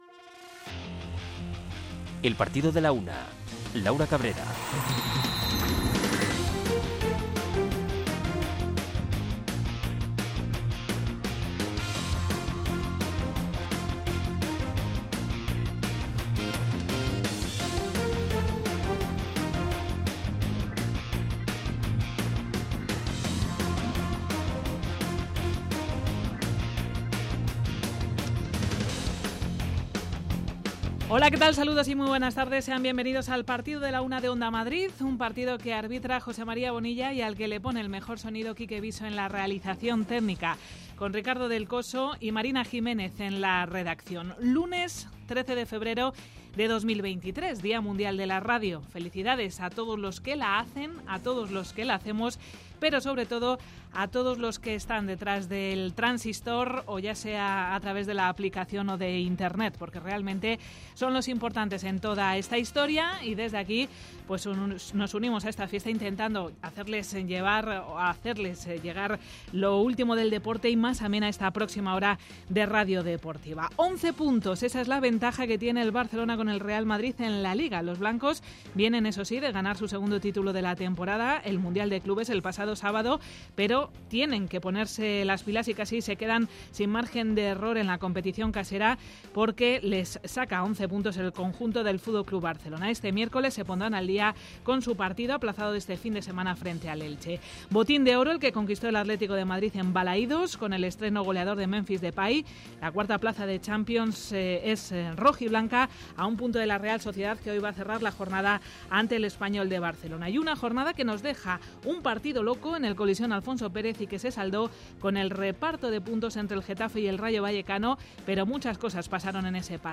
Arrancamos con la resaca de la jornada 21 en Primera División que nos deja la victoria in extremis del Atlético de Madrid en Balaidos ante le Celta de Vigo por 1-0. Escuchamos al técnico Diego Pablo Simeone y al capitán Koke Resurrección.